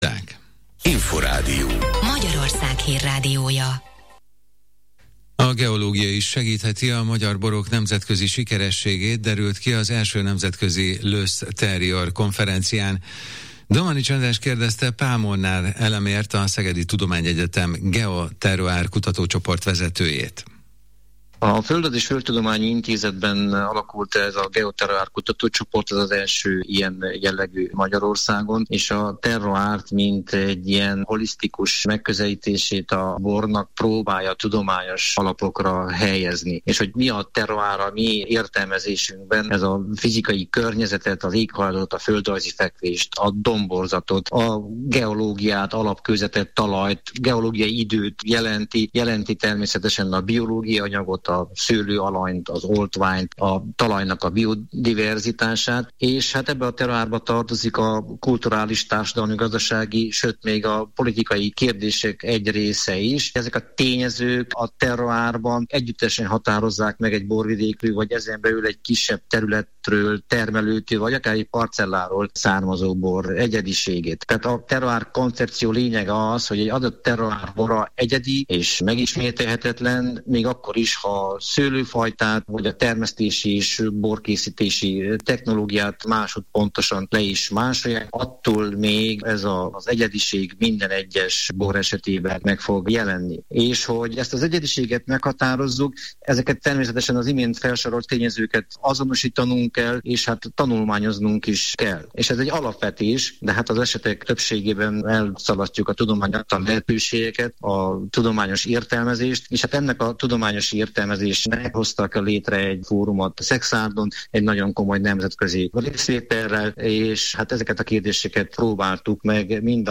Interjú